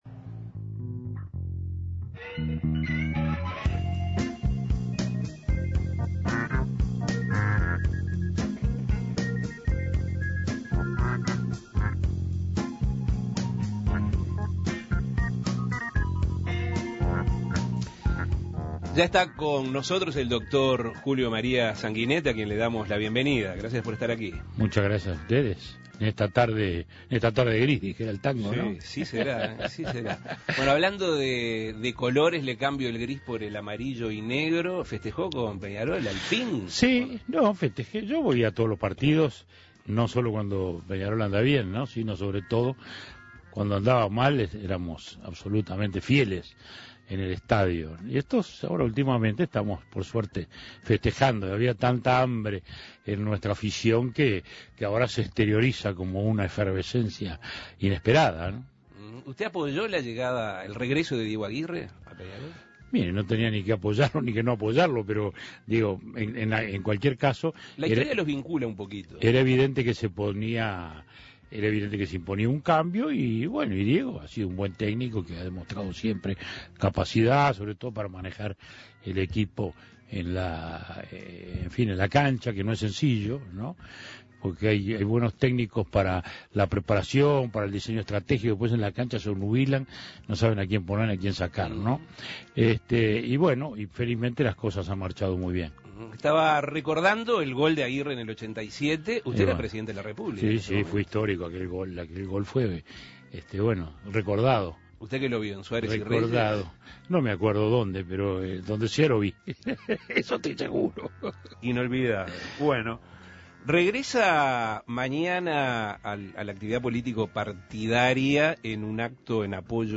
El doctor Julio María Sanguinetti, ex presidente de la República, dialogó sobre su situación actual en la actividad político partidaria, la idea de éste de habilitar la prisión domiciliaria a los mayores de 70 años, la incorporación del Plan Ceibal en la educación pública, si se abre una nueva era en el Partido Colorado con la llegada de Pedro Bordaberry a la Secretaría General, la implementación del Plan Ceibal, la Ley de Caducidad, entre otras cosas. Escuche la entrevista.